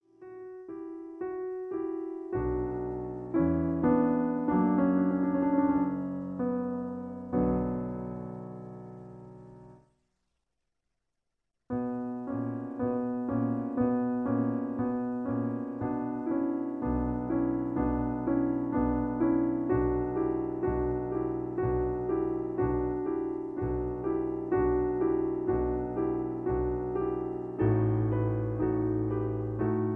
In B. Piano Accompaniment